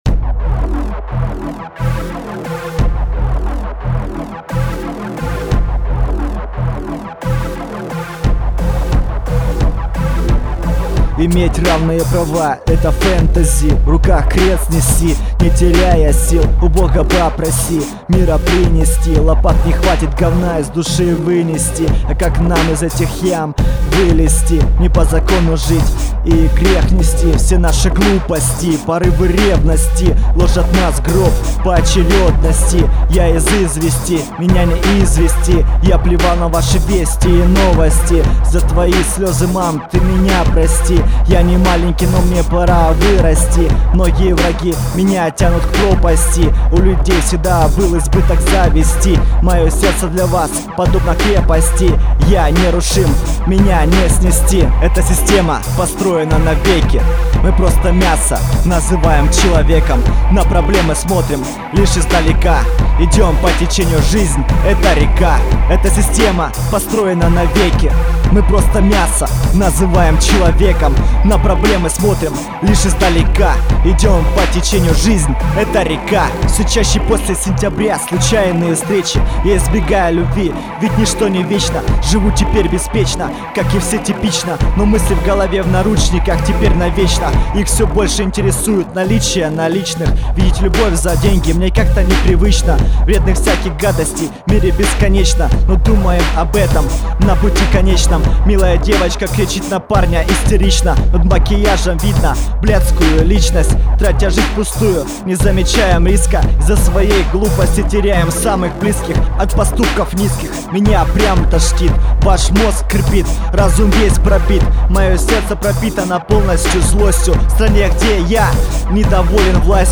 Rap & Hip-hop